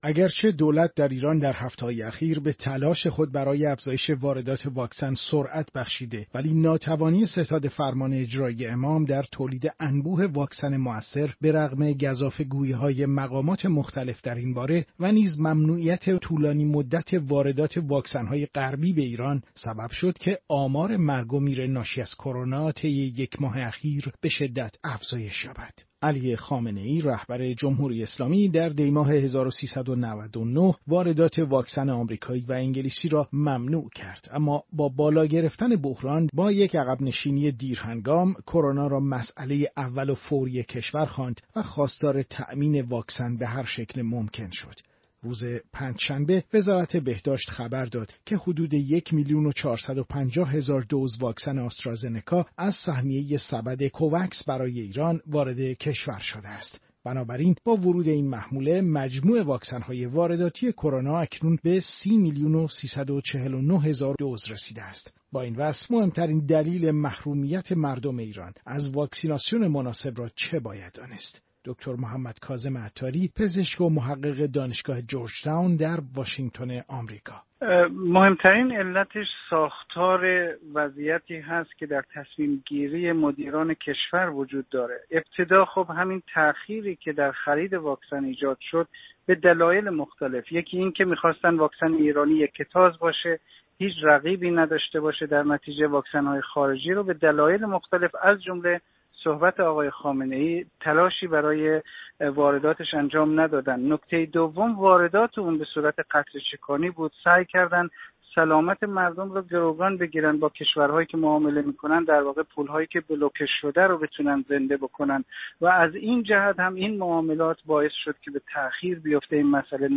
ایران در در حالی تنها ده درصد جمعیت خود را واکسینه کرده که همین رقم در ترکیه حدود ۴۷ درصد، در عربستان سعودی به ۴۴ درصد و در امارات به حدود ۷۸ درصد رسیده است. آخرین وضعیت واردات واکسن در ایران، در گزارش